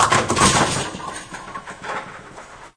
bowling-6.wav